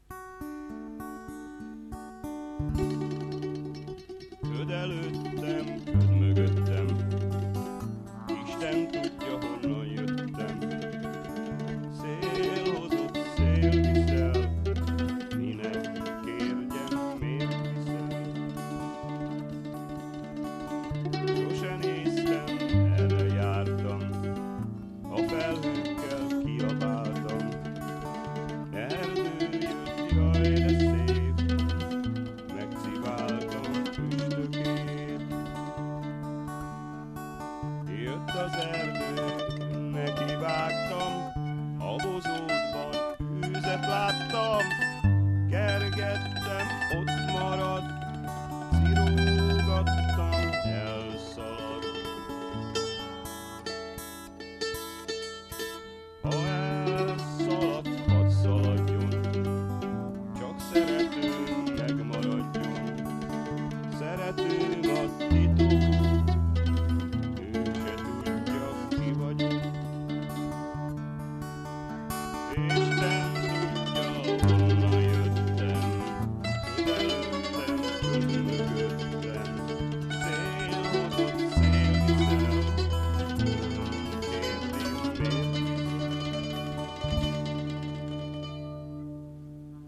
SOLO BAND SYSTEM - a másik lehetséges megoldás.
Stúdió? Többsávos keverőmagnó? Frászt! MOBILTELEFON!!!